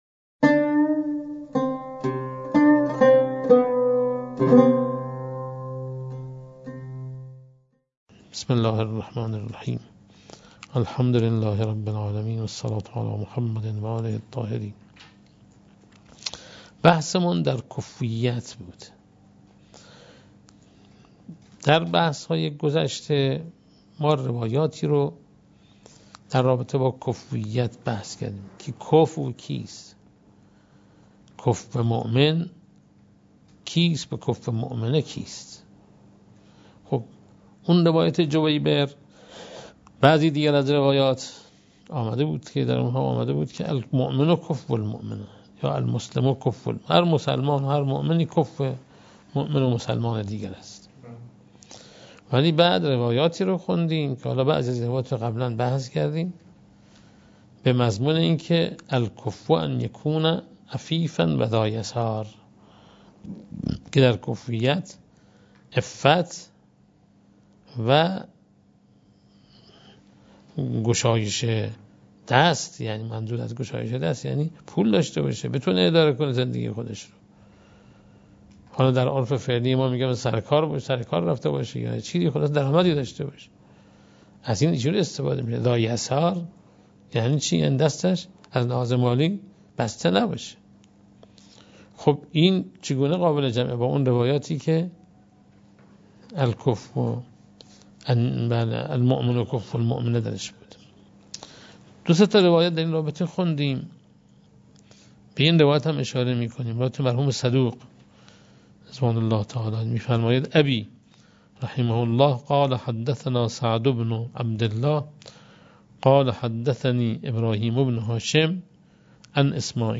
عوامل کفویت در ازدواج چیست؟ + صوتبه گزارش ایکنا، آیت‌الله محسن اراکی، استاد سطح عالی حوزه علمیه 17 آذرماه در ادامه مباحث فقه خانواده که در فضای مجازی برگزار شد، به بحث «کفویت برای ازدواج: عفاف و یسار» پرداخت و گفت: مراد از «عفاف»، عفاف جنسی و مراد از «یسار» توان تأمین مخارج زندگی است؛ روایتی در وسائل در باب 28 ذکر شده است که در آن این دو مورد لازمه کفویت برشمرده شده است؛ این روایت دارای سند معتبر است، همچنین روایات دیگری هم به این دو مورد اشاره کرده‌اند.
برچسب ها: اراکی ، فقه خانواده ، کفویت ، درس خارج ، ازدواج